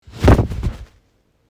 Download Body Fall sound effect for free.
Body Fall